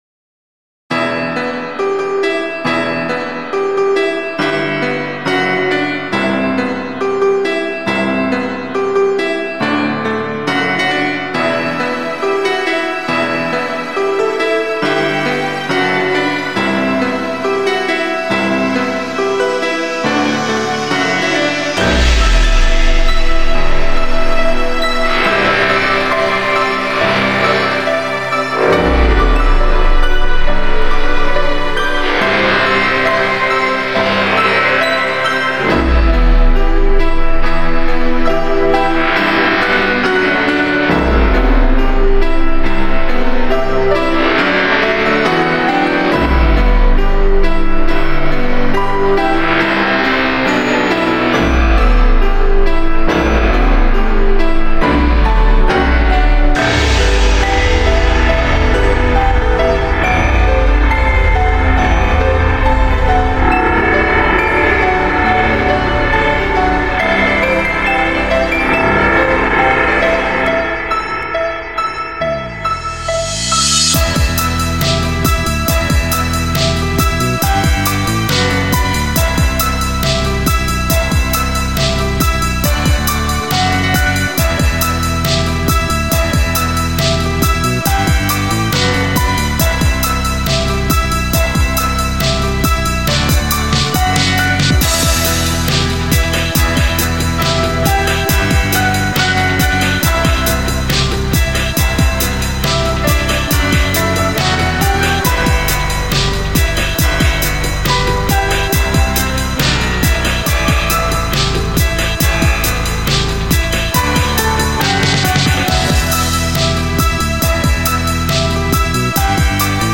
Žánr: Electro/Dance
NÁLADA ALBA JE PŘEVÁŽNĚ MELONCHOLICKÁ.